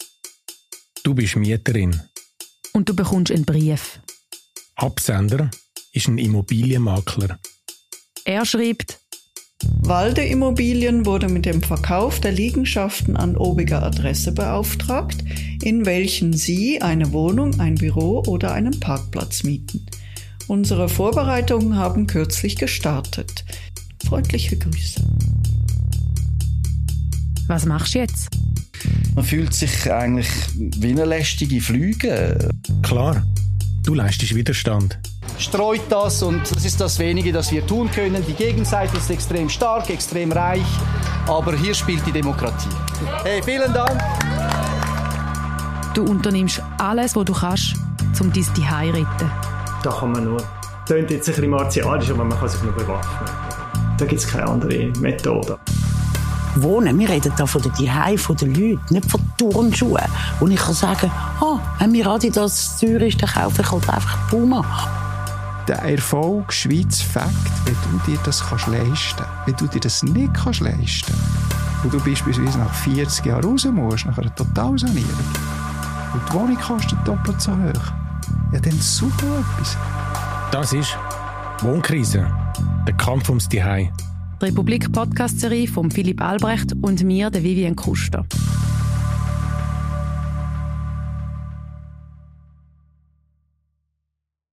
Ein dreiteiliger Doku-Podcast der Republik